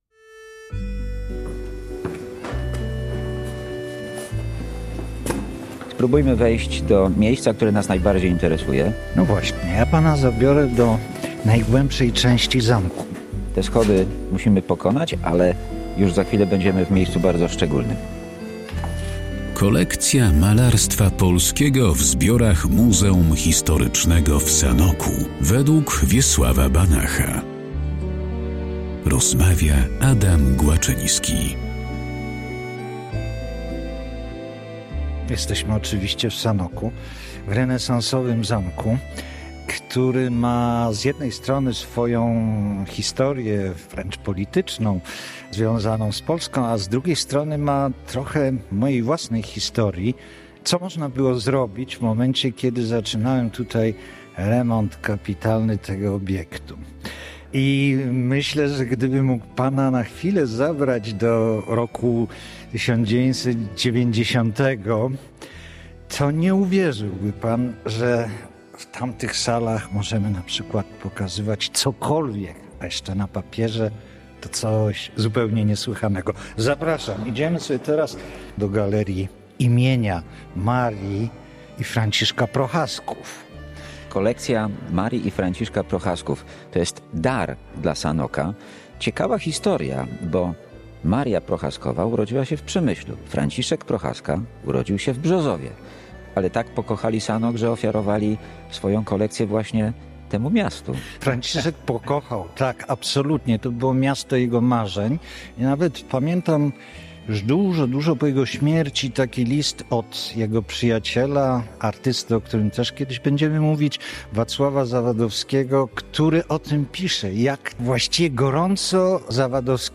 Obecnie kolekcja, liczy ponad 220 dzieł 69 artystów polskich i zagranicznych. Polskie Radio Rzeszów prezentuje cykl rozmów, w których przedstawieni zostaną wybrani autorzy i ich prace.